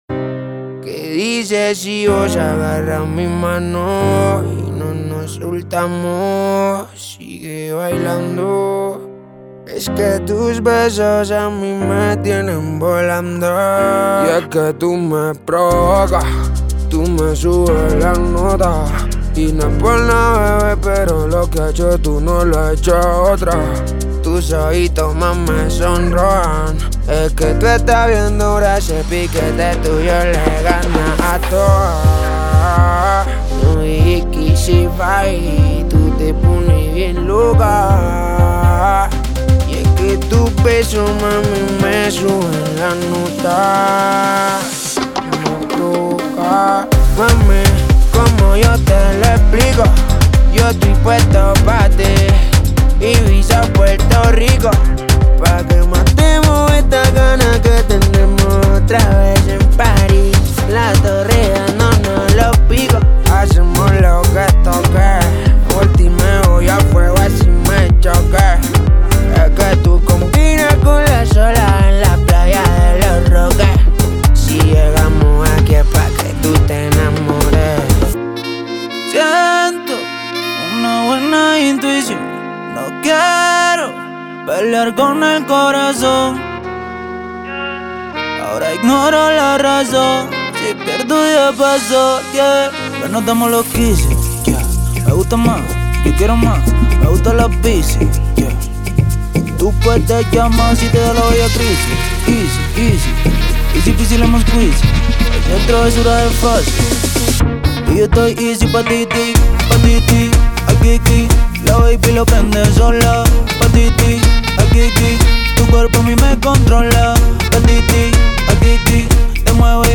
アフロハウス系では特に使い勝手も良く、 重宝するサンプルパックです。
Genre:Afro House
ご注意：オーディオデモは、大音量かつコンプレッションされた均一なサウンドに仕上げるために加工されています。
122 – 128 BPM